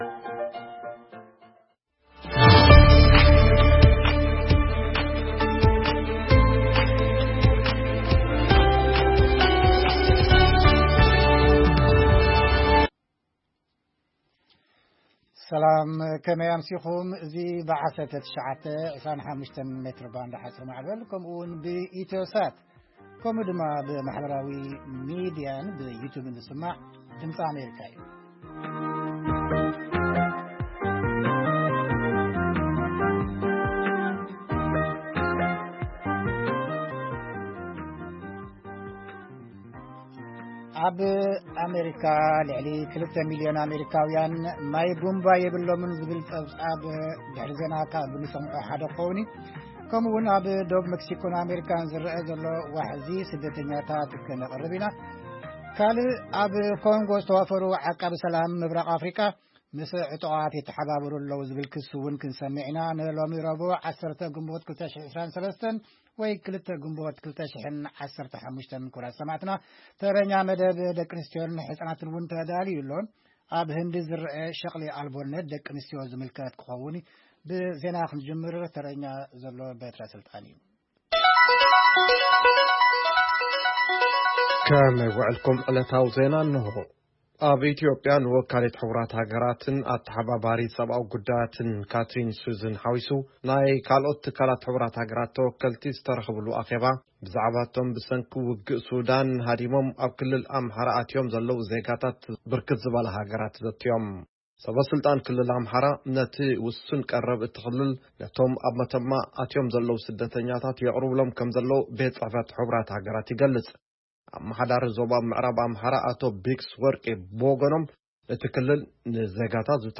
ፈነወ ድምጺ ኣመሪካ ቋንቋ ትግርኛ 10 ግንቦት 2023 ዜና (ተወከልቲ ዝተፈላለያ ትካላት ሕ/ሃ ካብ ኲናት ሱዳን ሃዲሞም መተማ ዝኣተው ሰባት ዝምልከት ኣኼባ ኣካይዶም፡ቱርኪ ምርጫ ከተካይድ'ያ ዝብሉን ካልኦትን) መደብ ደቂ ኣንስትዮን ስድራቤትን የጠቓልል።